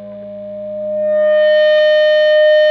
PRS FBACK 5.wav